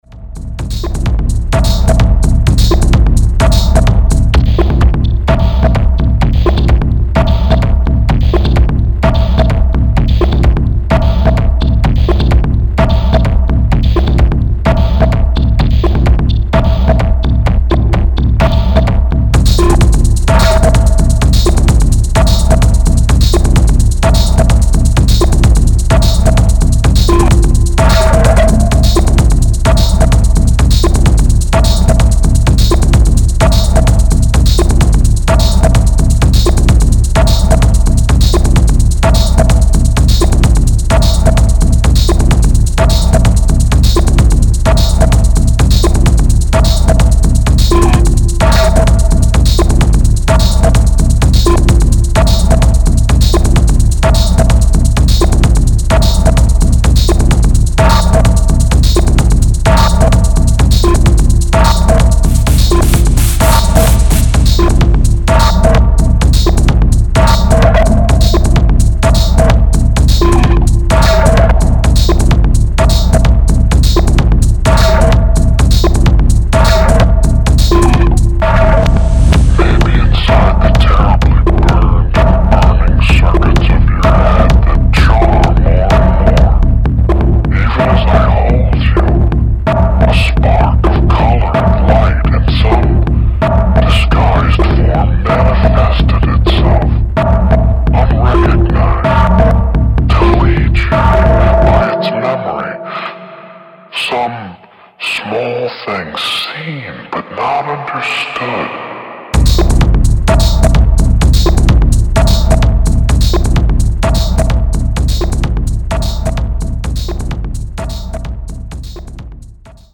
Dark Techno from Dublin on blood red vinyl
Style: Techno / Dark Techno